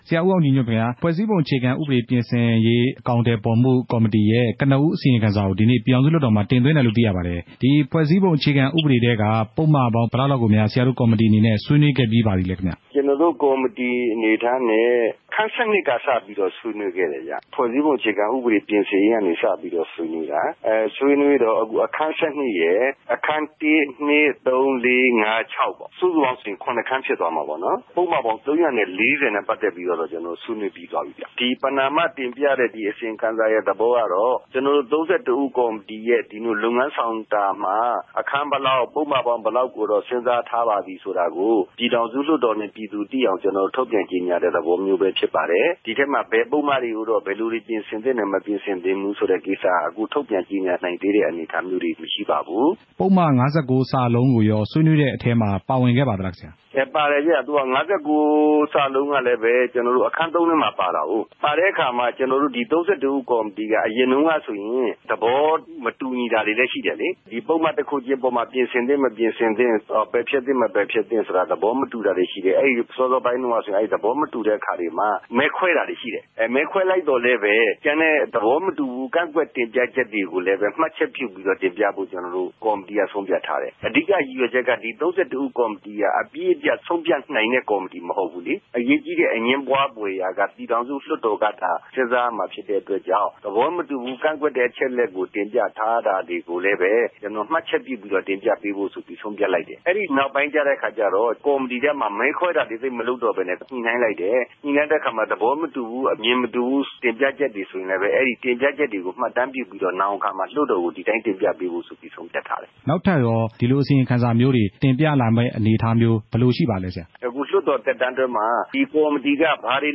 ဦးအောင်ကြည်ညွန့်နဲ့ မေးမြန်းချက်